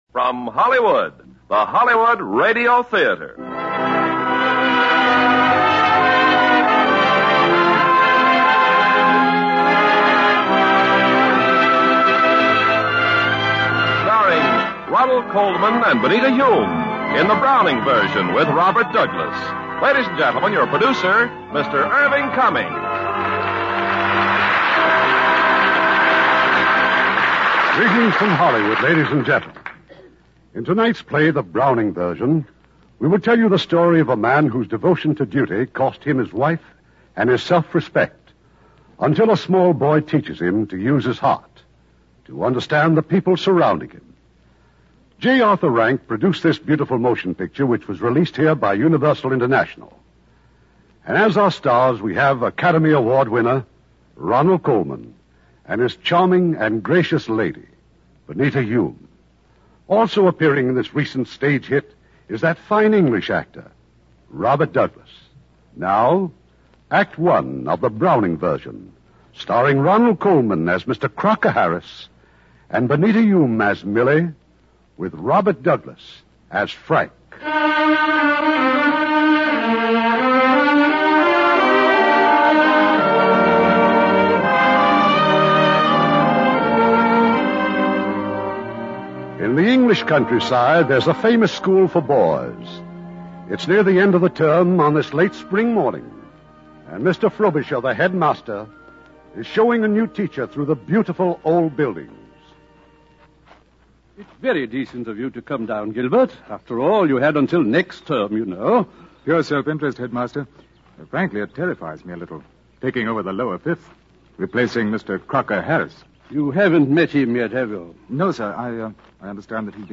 The Browning Version, starring Ronald Colman, Benita Hume